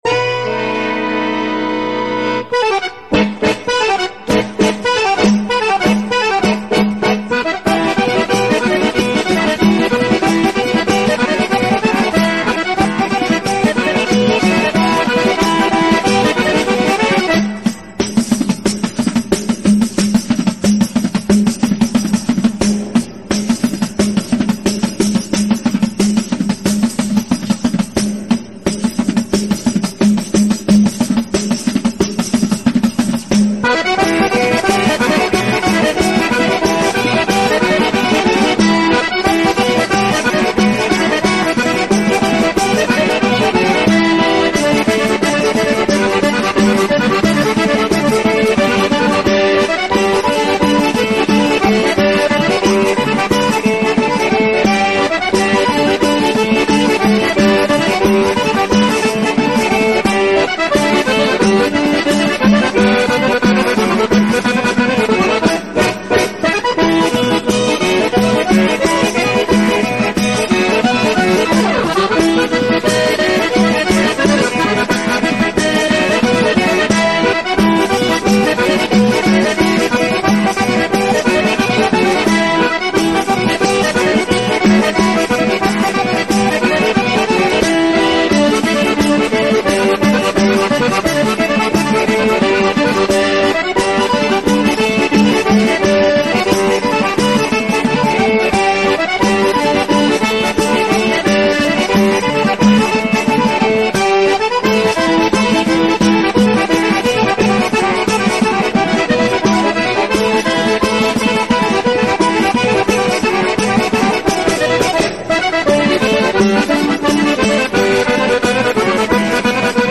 Tarantella-Pugliese-Foggia-strumentale.mp3